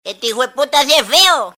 Descarga de Sonidos mp3 Gratis: ringtone hp feo.